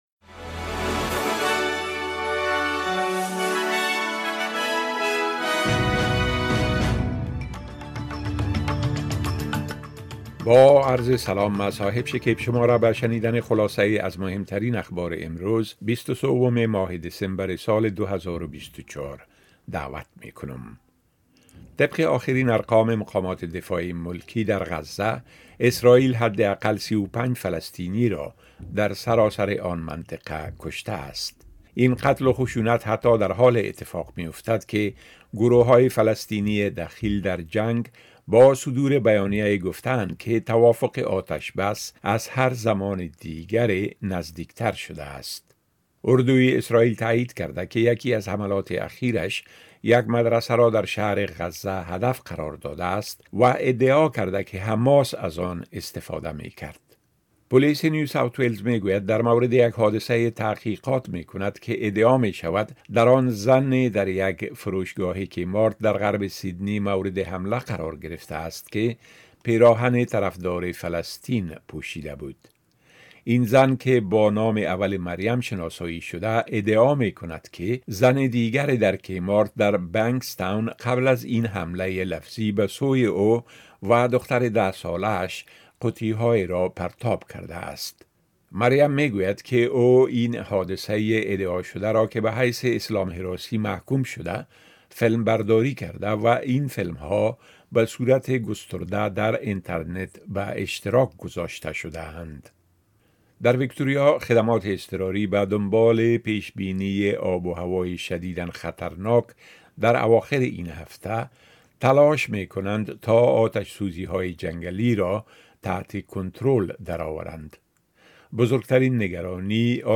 10 am News Update Source: SBS / SBS Filipino